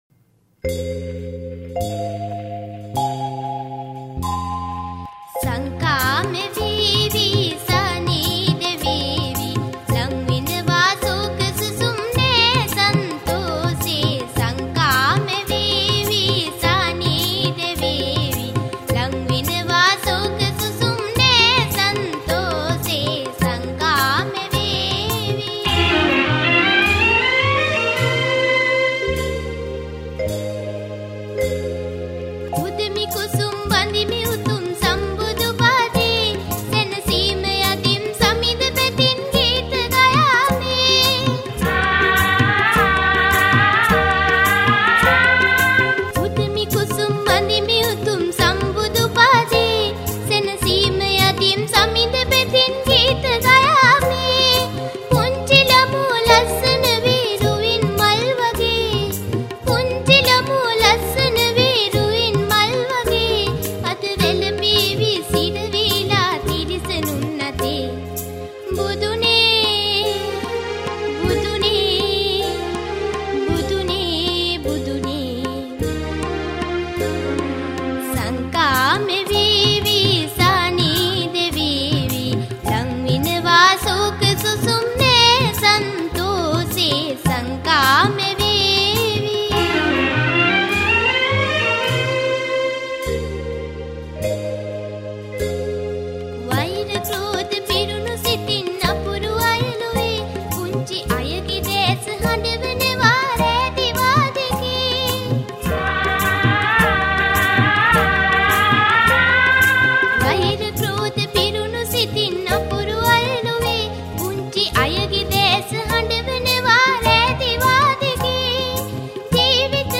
Sinhala Lama Gee Mp3 Download